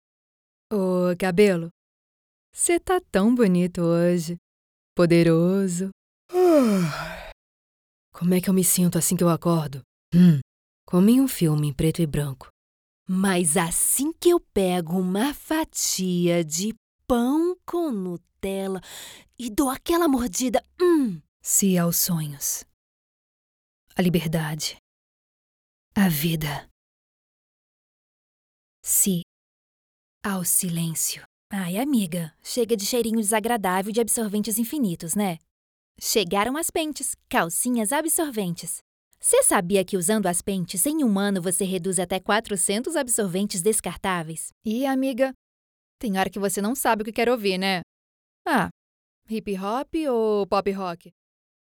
Her voice is described as versatile, friendly, conversational and her voice range goes from 12 to 35 years old.
Sprechprobe: Werbung (Muttersprache):